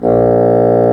Index of /90_sSampleCDs/Roland L-CDX-03 Disk 1/WND_Bassoons/WND_Bassoon 4
WND CSSN A#1.wav